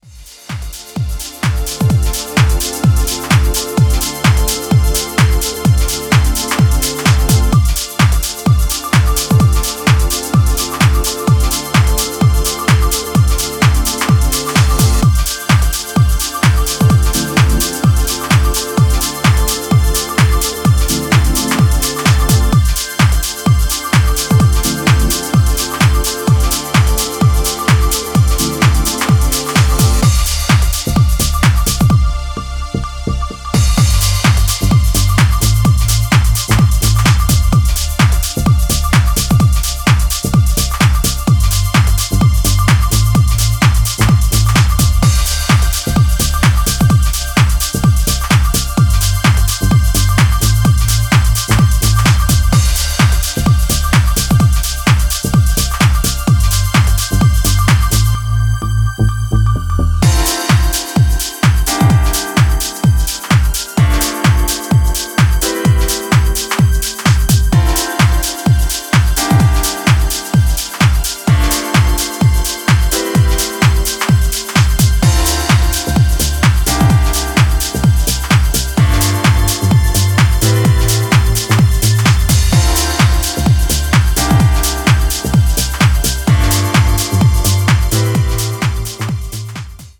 UKレイヴとUSハウスの折衷を試みるような、ピークタイム仕様の意欲作です。